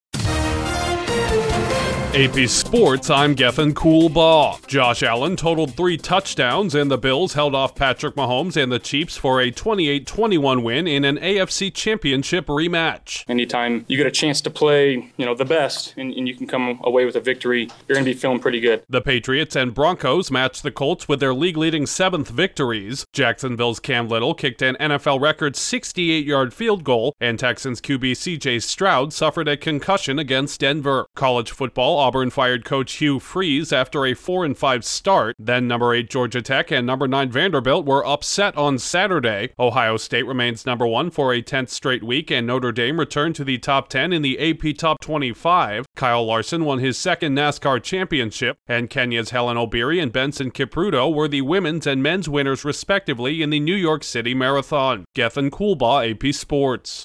Headliner Embed Embed code See more options Share Facebook X Subscribe Josh Allen bests Patrick Mahomes in latest clash of star quarterbacks, three teams share the NFL lead in wins, a record-long field goal and a star QB is injured, an SEC football coach is fired and two top 10 upsets result in AP Top 25 changes, a two-time NASCAR champ and results from the NYC Marathon. Correspondent